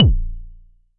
016 SH32 Kick.wav